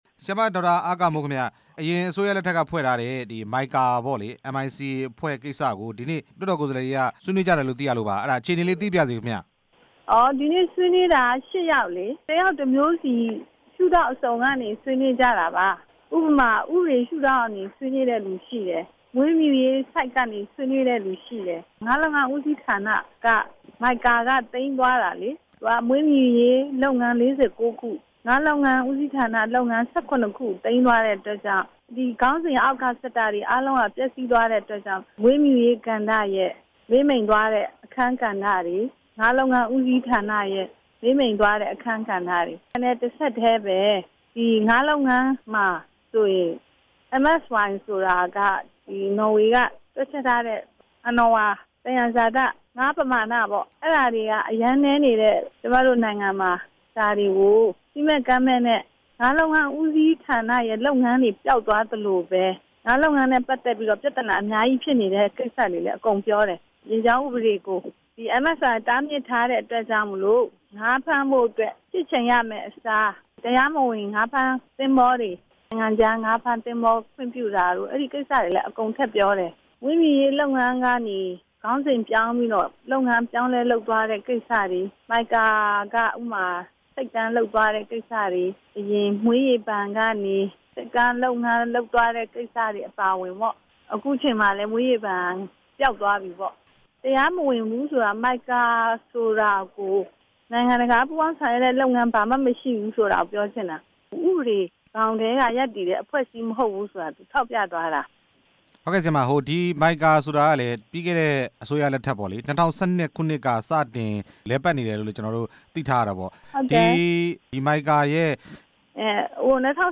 MICA အဖွဲ့ ဖျက်သိမ်းအဆိုတင်ခဲ့တဲ့ ဒေါက်တာအားကာမိုး နဲ့ မေးမြန်းချက်